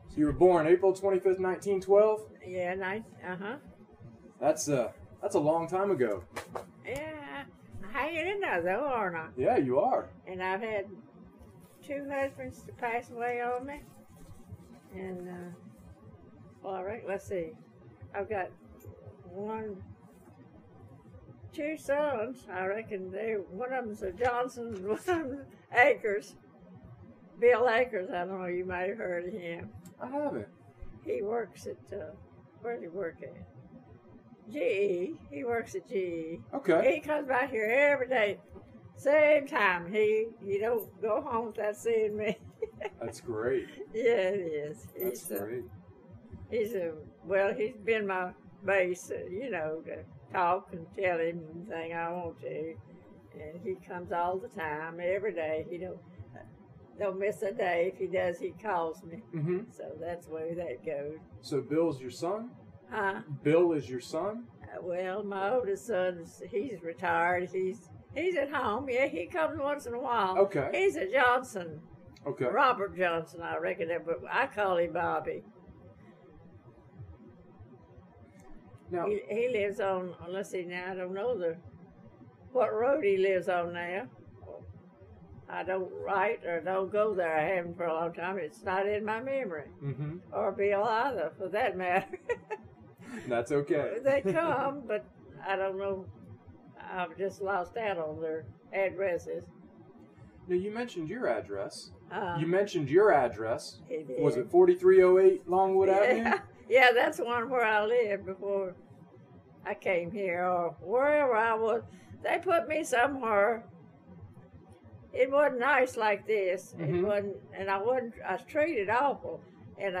Neighborhood Oral History Project